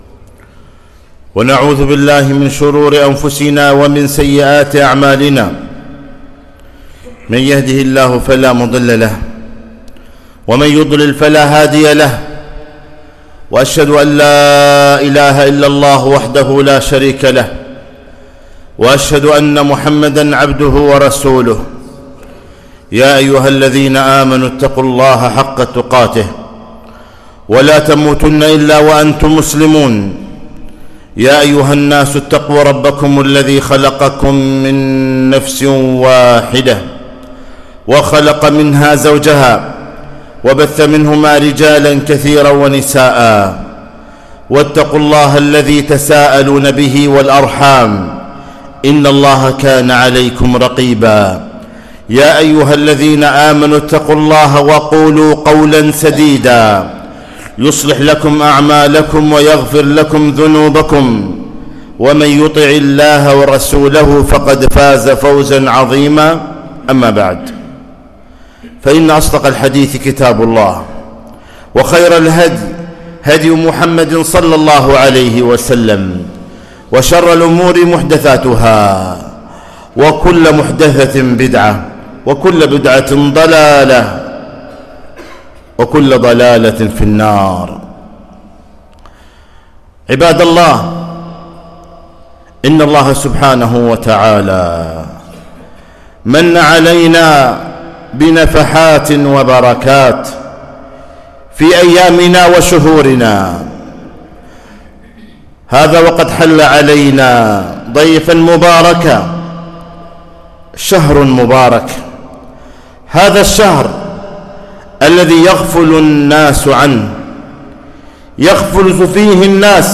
خطبة - شهر يغفل الناس عنه